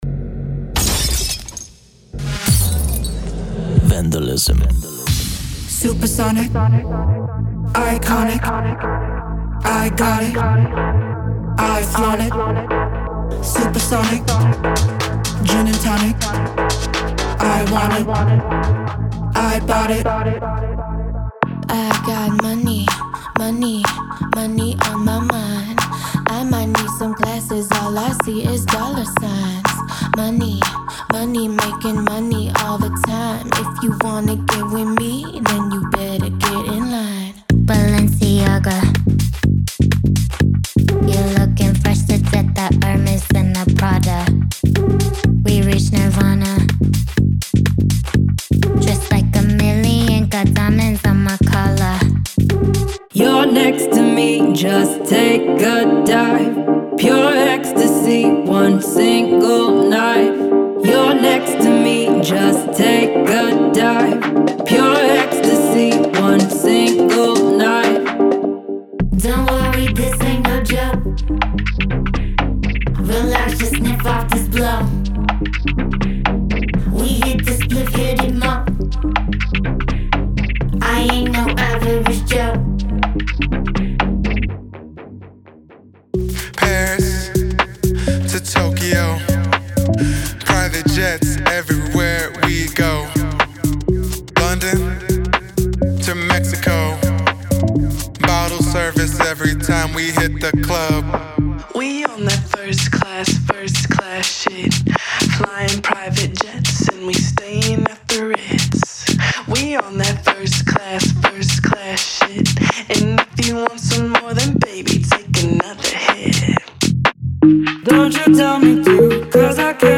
Type: Samples
Deep House Tech House Techno
• 56 Vocal Phrases & Chops
• 25 Dry Vocal Phrases
• 21 Wet Vocal Phrases
• 124 & 125 BPM